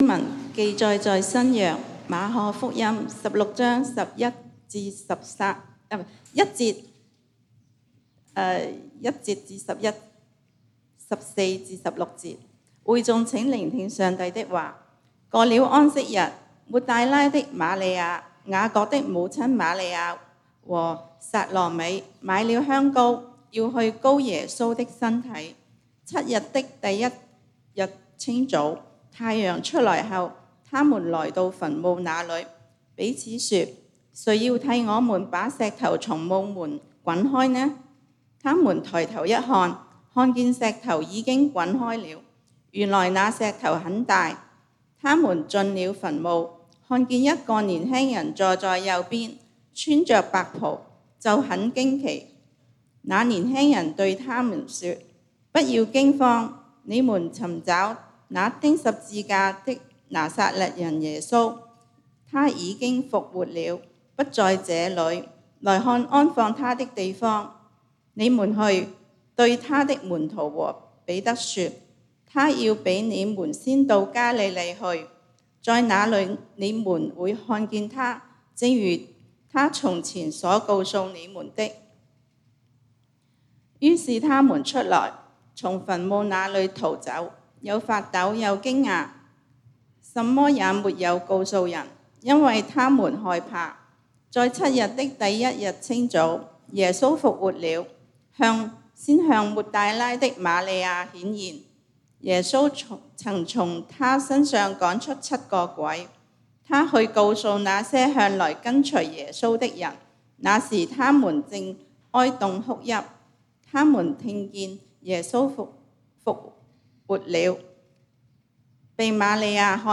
4/4/2021 講道經文：《馬可福音》Mark 16：1-11，14-16 使徒行傳 2:32